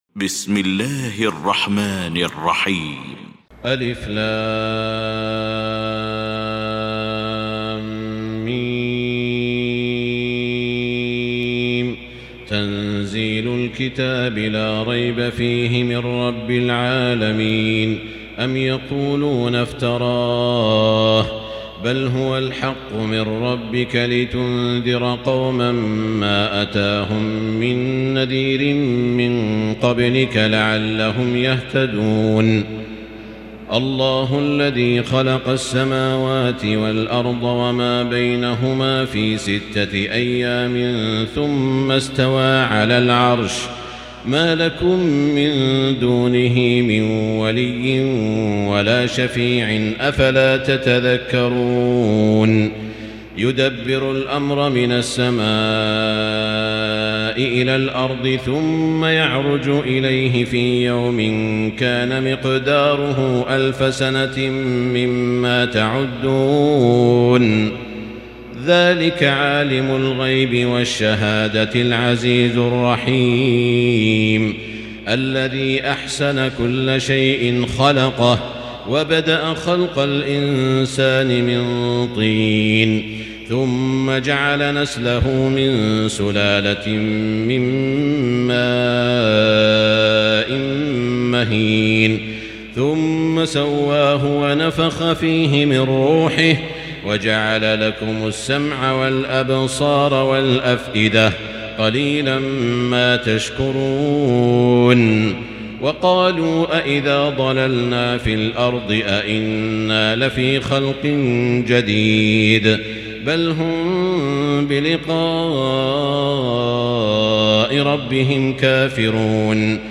المكان: المسجد الحرام الشيخ: سعود الشريم سعود الشريم السجدة